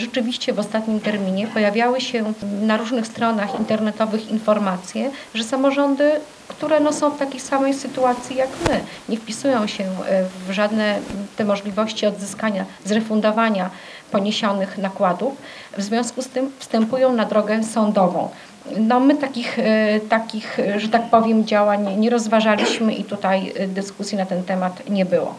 Wystąpienie prezydent to odpowiedź na interpelację Zbigniewa de Mezera.